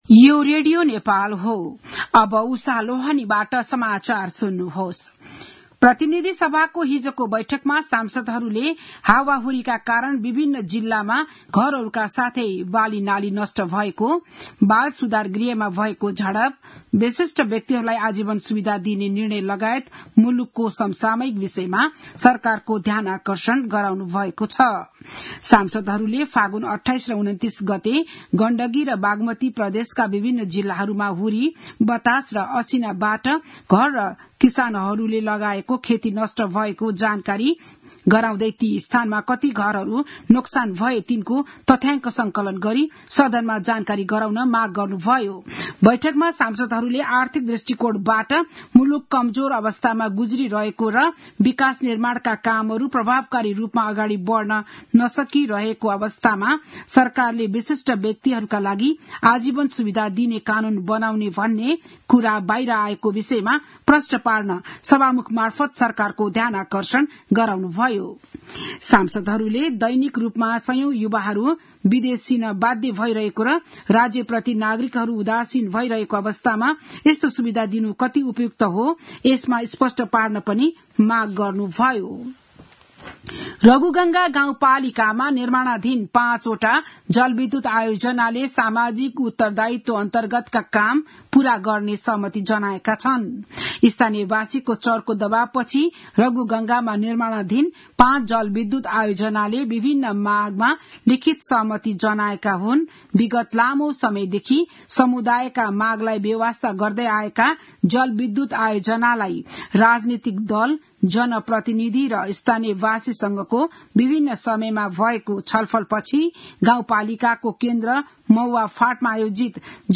बिहान ११ बजेको नेपाली समाचार : ४ चैत , २०८१